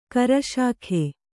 ♪ karaśakhe